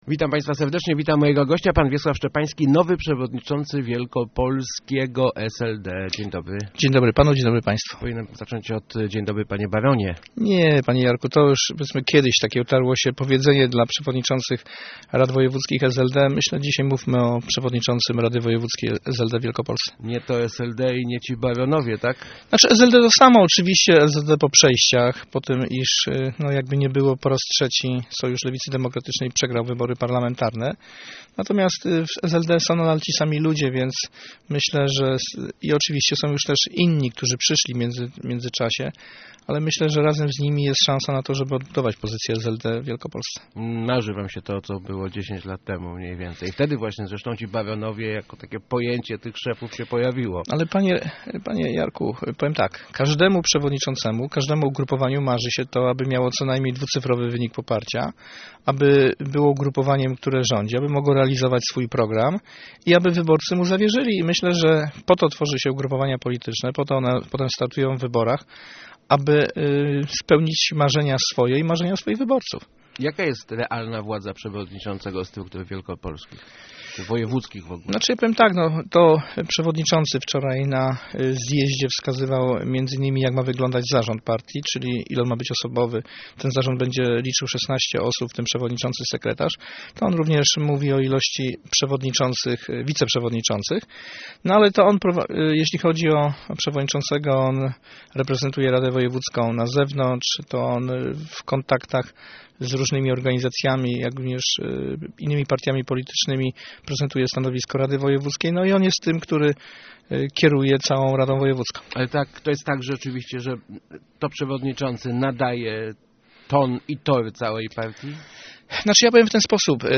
Odebrać Wielkopolskę Platformie - to strategiczny cel, jaki stawia sobie Wiesław Szczepański, nowy przewodniczacy Rady Wojewódzkiej SLD. Jak powiedział w Rozmowach Elki, można ten cel osiągnąć przez powrót do ideałów Lewicy i przekonanie do nich młodych ludzi, na przykład protes...